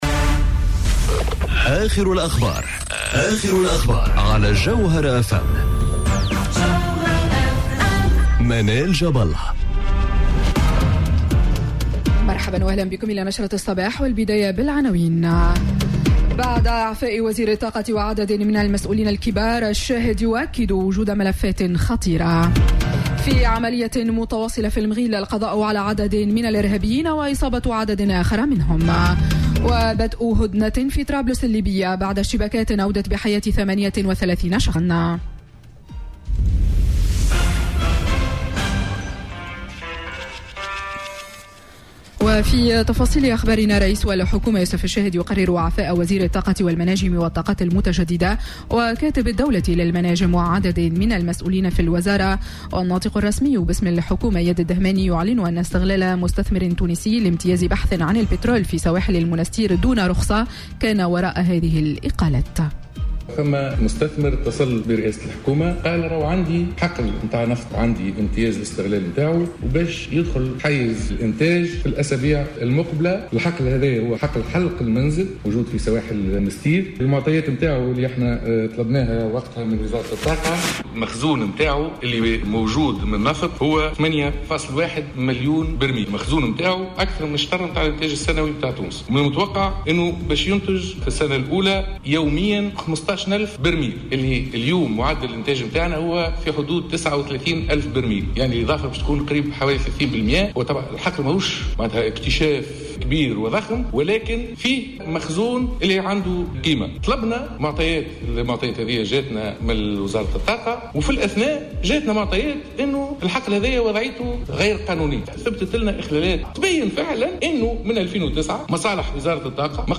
نشرة أخبار السابعة صباحا ليوم السبت 01 سبتمبر2018